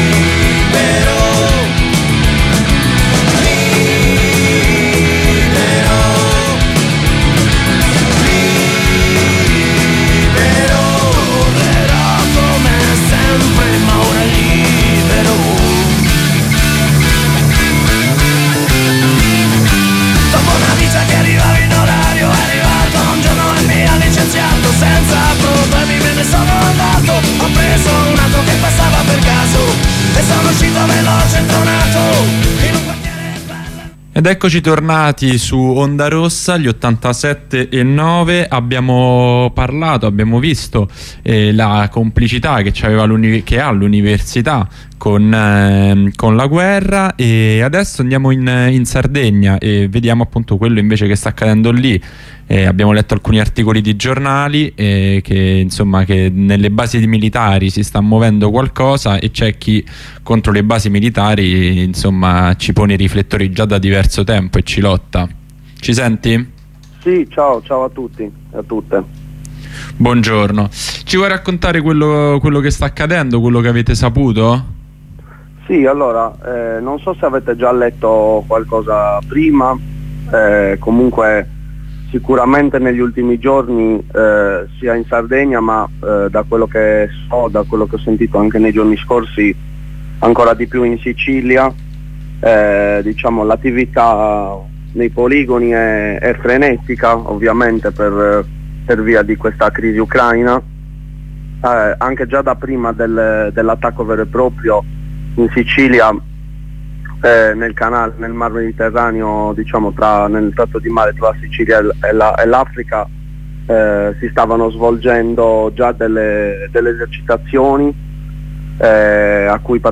Collegamento con un compagno sardo che ci racconta i movimenti all'interno delle basi militari in questo momento di riacutizzazione della guerra in Ucraina.